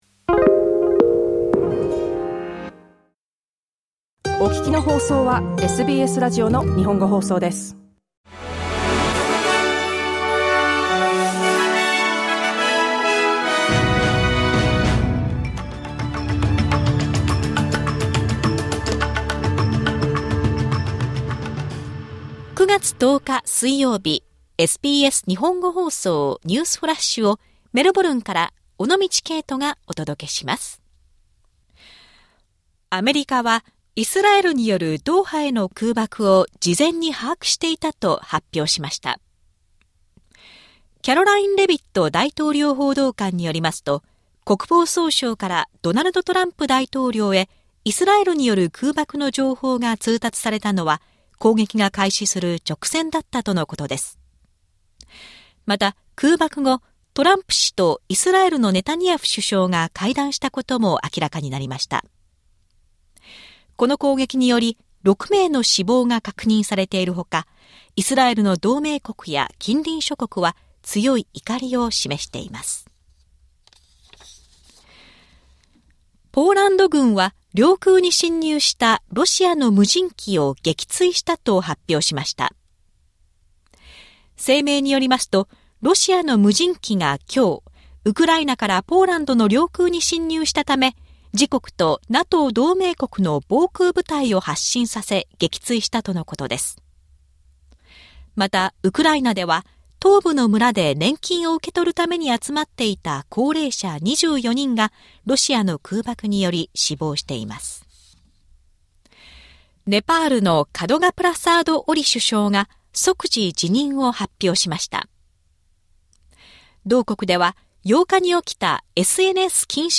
SBS日本語放送ニュースフラッシュ 9月10日 水曜日